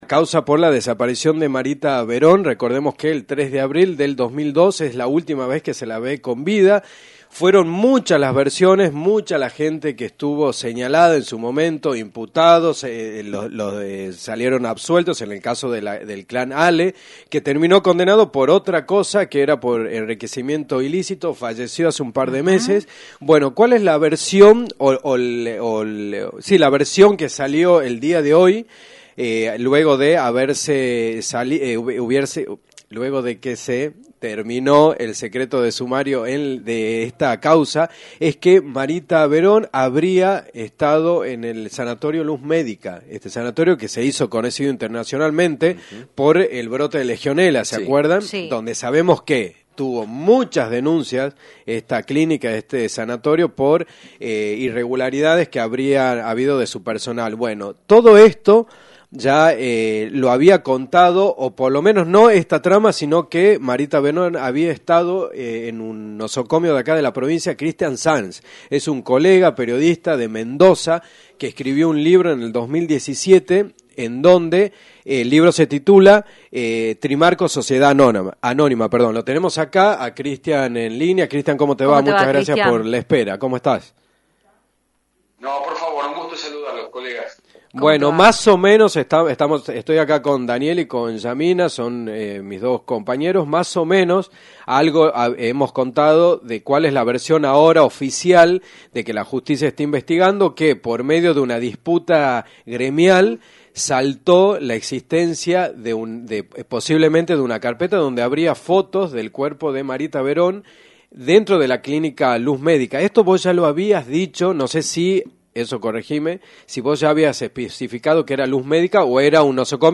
pasó por los micrófonos de Dos a la Tarde en Rock and Pop Tucumán